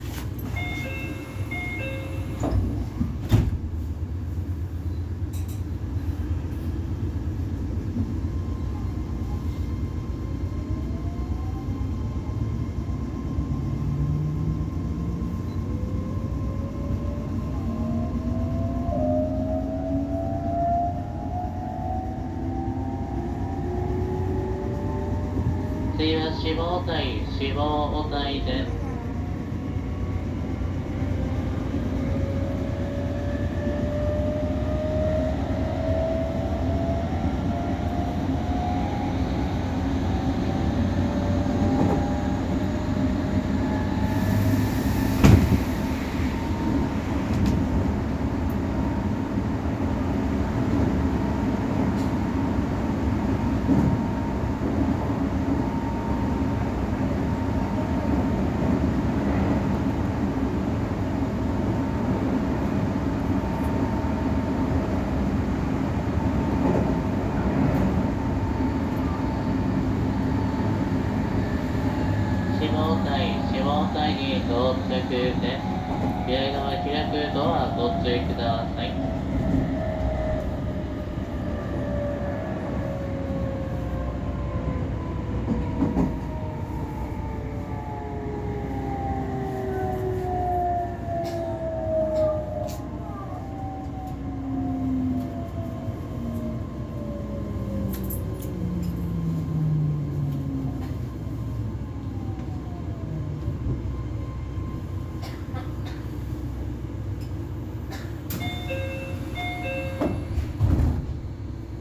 ・3500系(更新車)走行音
【本線】中小田井→下小田井
更新前がよくある東洋GTOだったのと同様、更新後もよくある東洋IGBTとなりました。特筆すべきような音ではありませんが、乗った印象としては新車と遜色ない車両になっているように思えました。